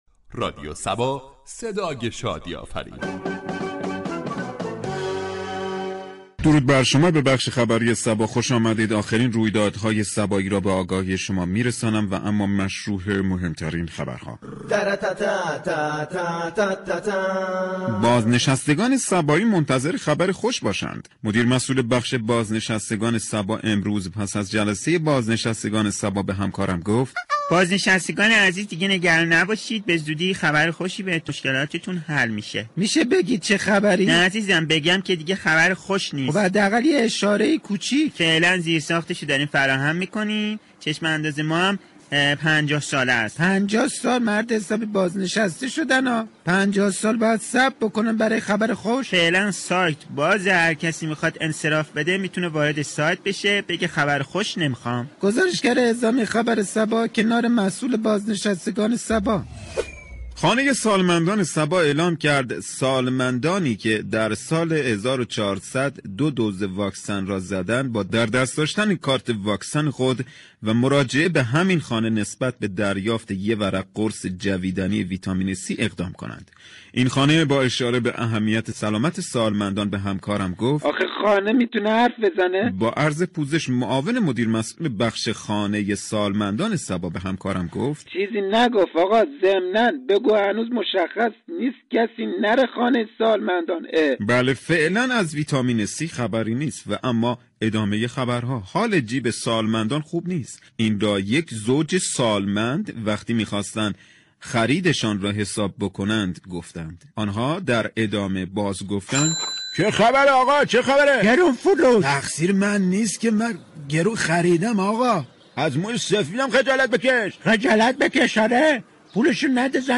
برنامه زنده "عصر صبا " در قالب مجله عصرگاهی با بیان موضوعاتی اجتماعی و حقوق شهروندی را بیان می كند .
در ادامه شنونده بخش نمایش طنز عصر صبا با موضوع مشكلات دوران بازنشستگی باشید.